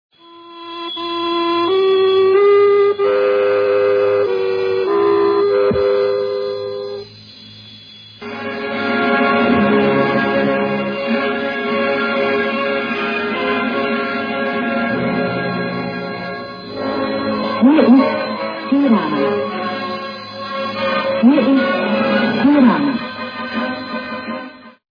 Des voix lointaines entraient dans ma chambre brouillées par la distance. Un peu avant minuit Radio Tirana donnait, en français, des cours de marxisme-léninisme.